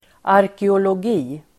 Uttal: [arkeolåg'i:]